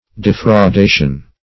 Search Result for " defraudation" : The Collaborative International Dictionary of English v.0.48: Defraudation \De`frau*da"tion\, n. [L. defraudatio: cf. F. d['e]fraudation.] The act of defrauding; a taking by fraud.
defraudation.mp3